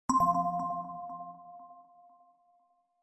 В коллекции – системные оповещения, сигналы сканирования и другие характерные аудиофрагменты длиной от 1 до 6 секунд.
Звук сигнала вопросительного знака